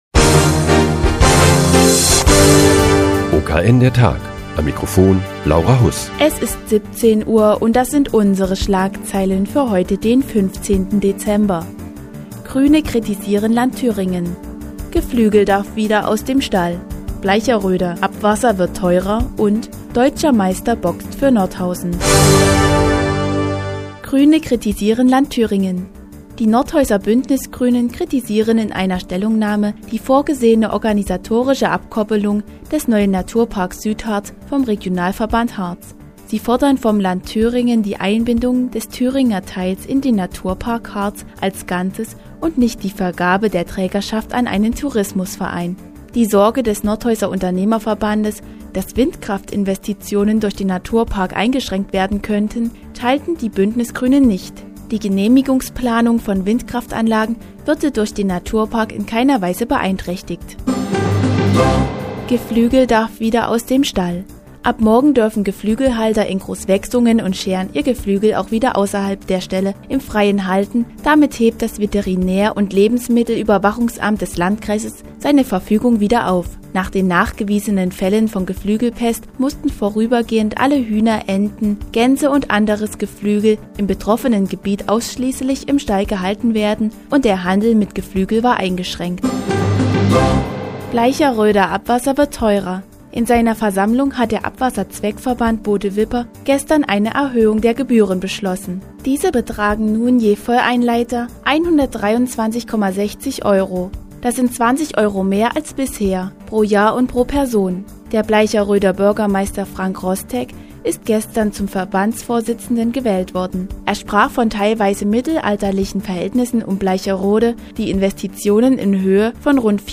Die tägliche Nachrichtensendung des OKN ist nun auch in der nnz zu hören. Heute geht es um die Versammlung des Abwasserzweckverbandes Bode-Wipper und den Neuzugang im Bundesliga-Team der Nordhäuser Boxer.